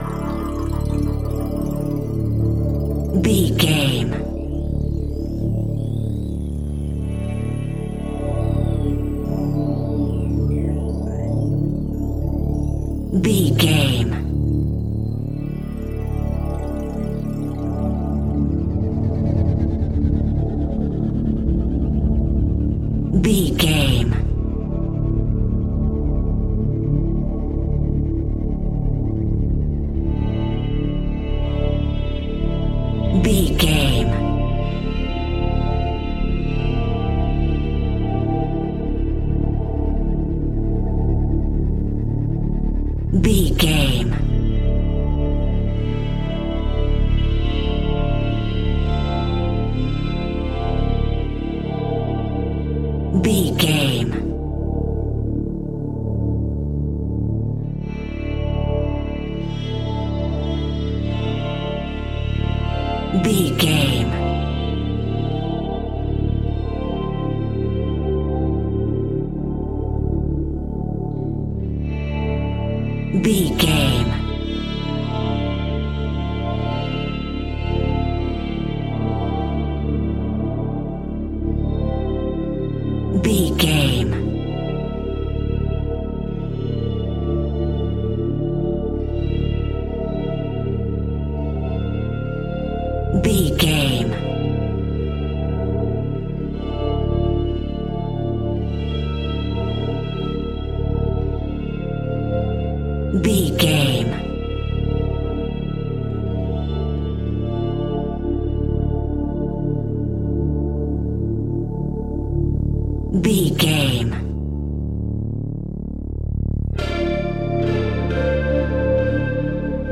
Thriller
Aeolian/Minor
Slow
synthesiser
electric guitar
tension
ominous
suspense
haunting
creepy